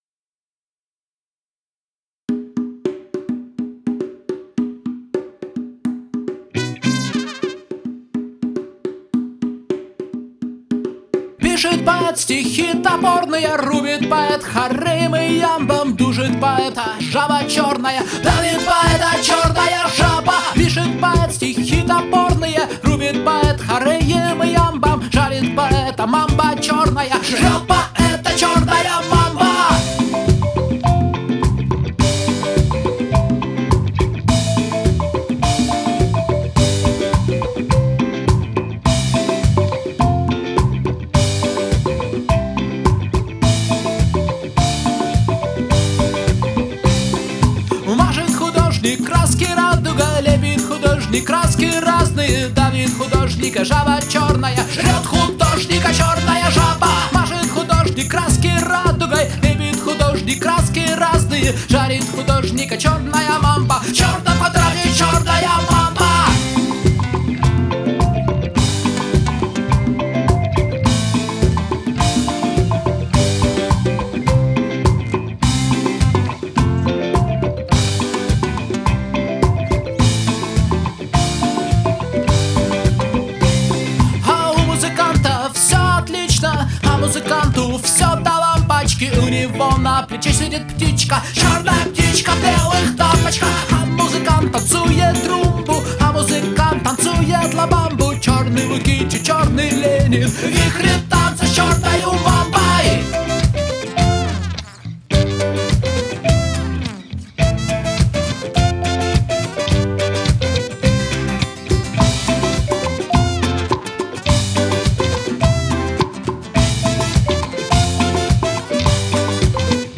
Латина/не латина, всё в кучу, но вроде бы забавная.